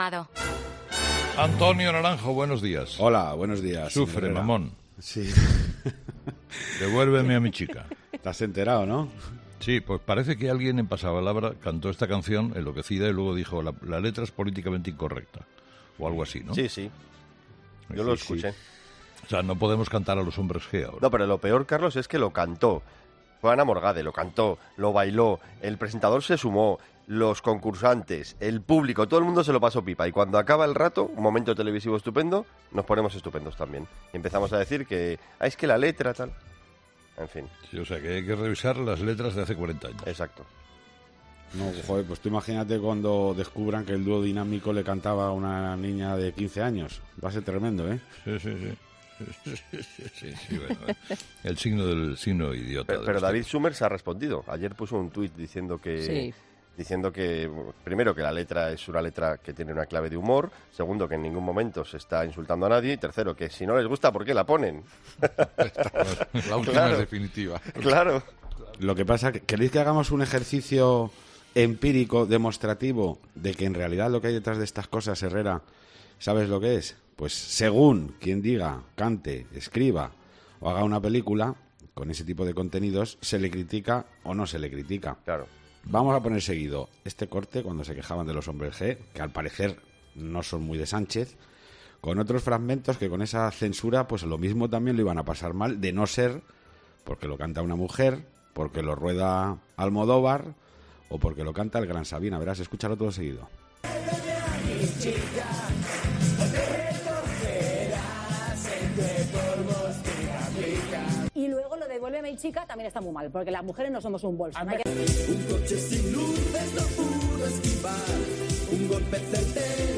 "Vamos, el revisionismo histórico...", dice Herrera muy irónico.